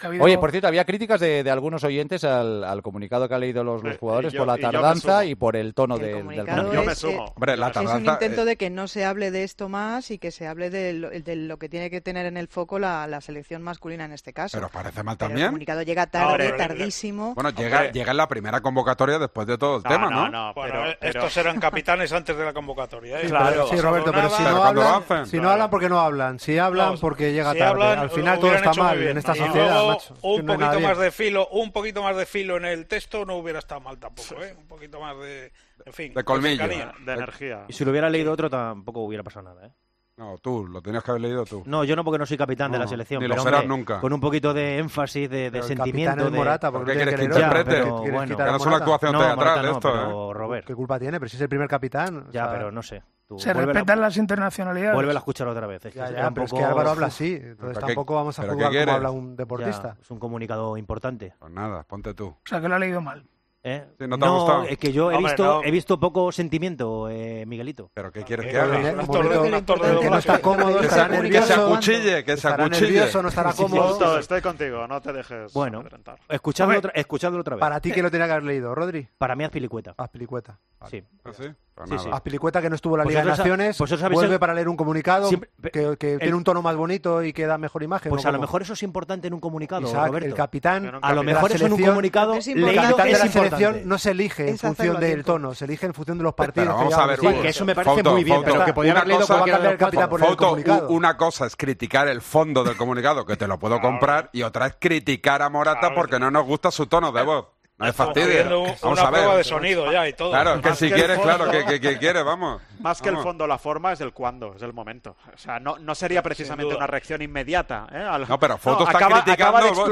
El Partidazo de COPE debate sobre el comunicado leído por los capitanes de España